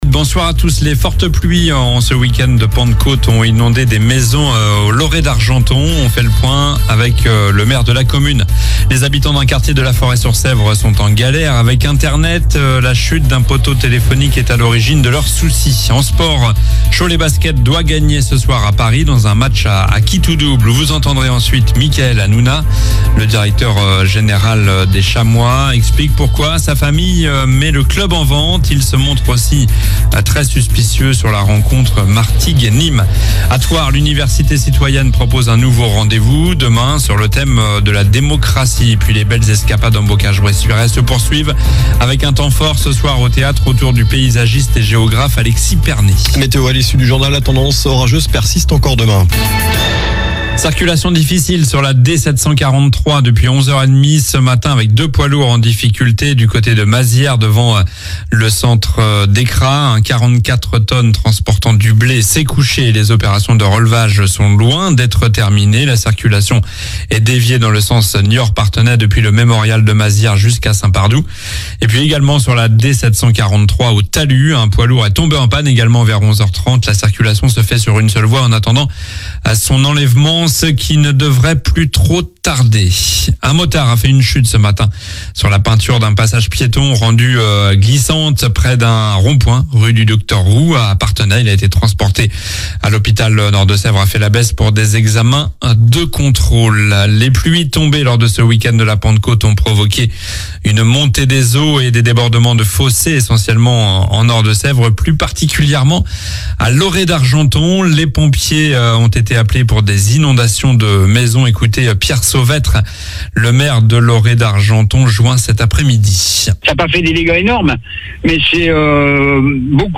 Journal du mardi 21 mai (soir)